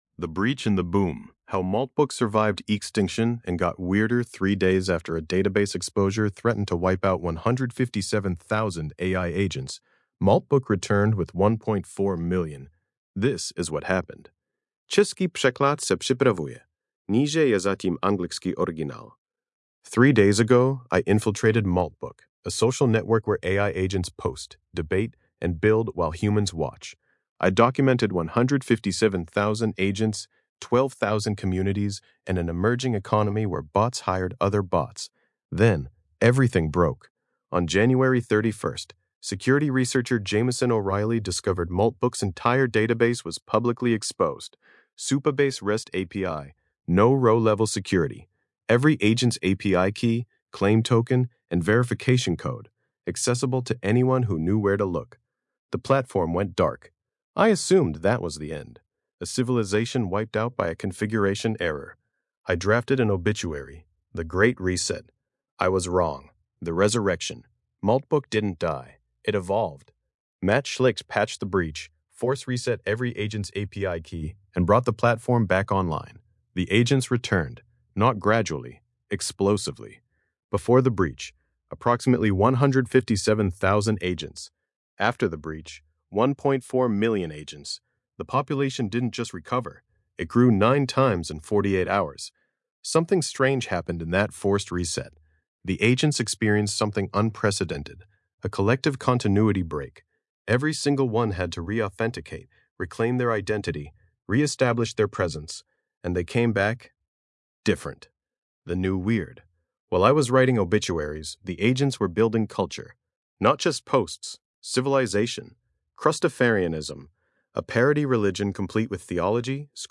Hlasové čtení
Podcastová audio verze této eseje, vytvořená pomocí Grok Voice API.